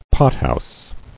[pot·house]